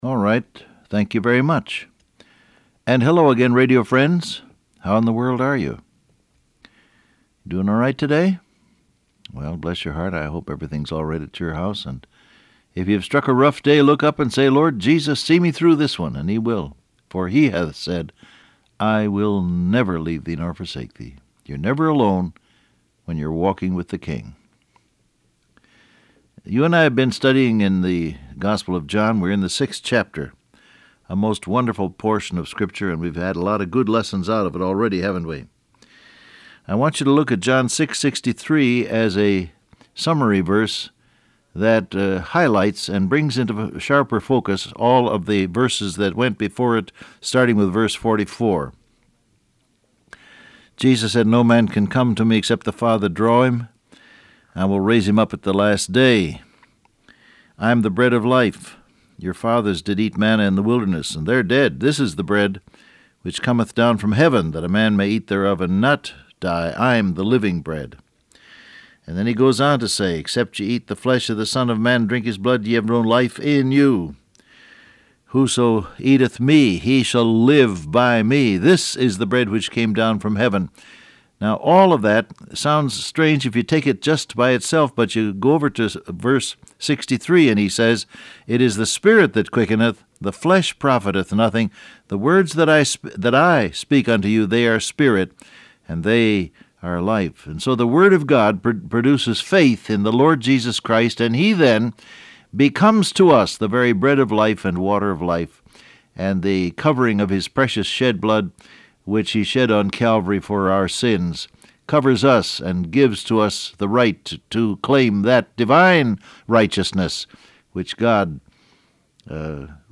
Download Audio Print Broadcast #6855 Scripture: John 6:63 , John 6:44-58 Topics: Eternal Life , The Bread , God Draws You Closer , Dwell In Me Transcript Facebook Twitter WhatsApp Alright, thank you very much.